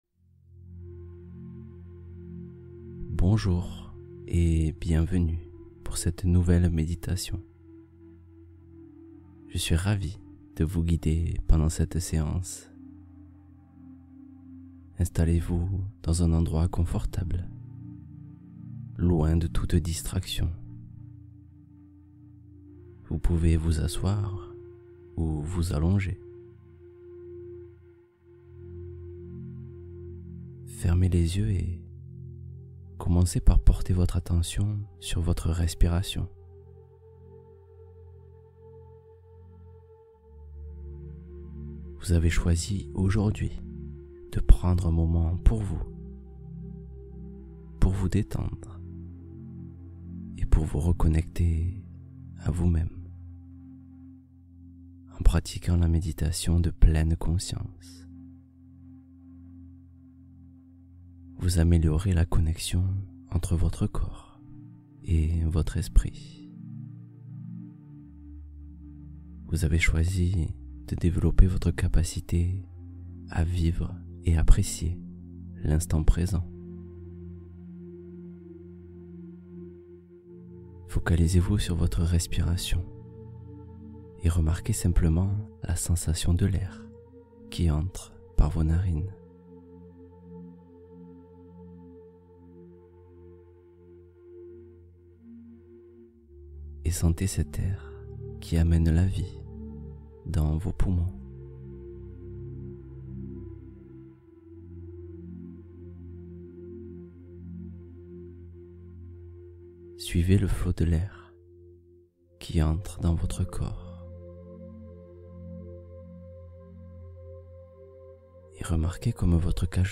Paix intérieure : méditation guidée pour revenir au bien-être